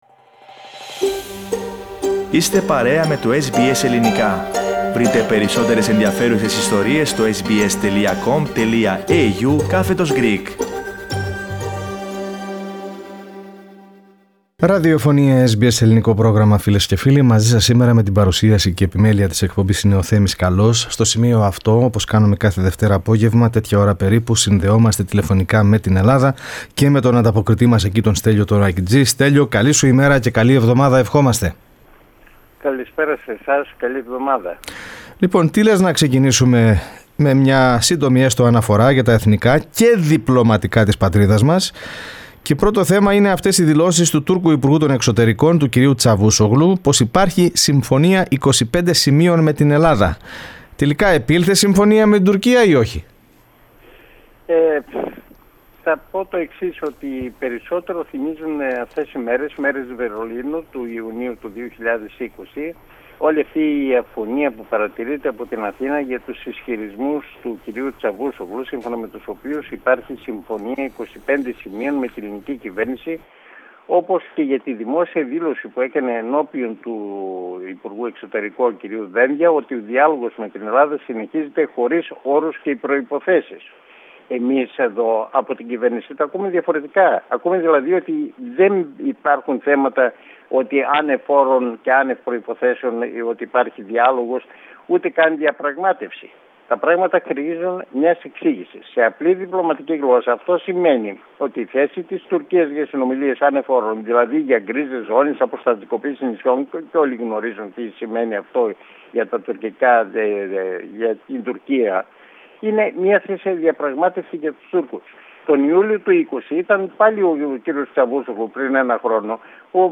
Ελληνοτουρκικά, πανδημία και νέα από την πολιτική ζωή στην Ελλάδα στην ανταπόκριση από την Αθήνα (7.6.2021).